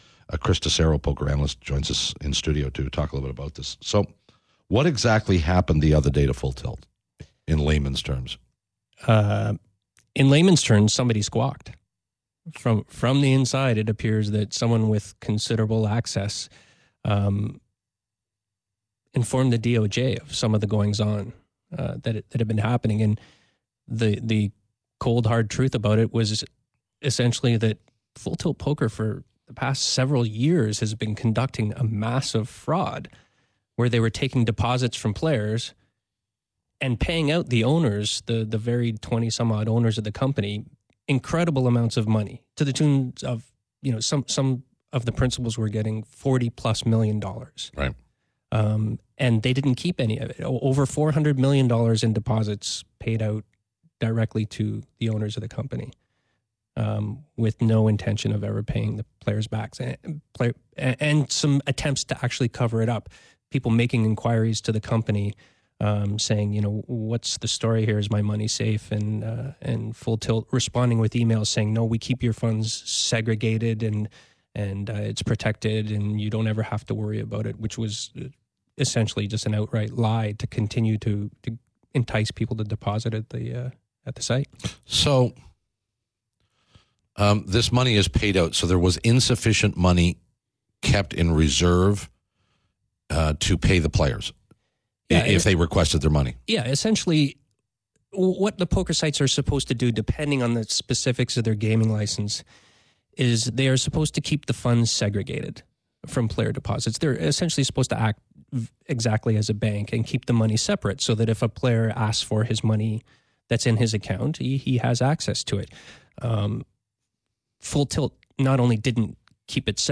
Very interesting and depressing interview.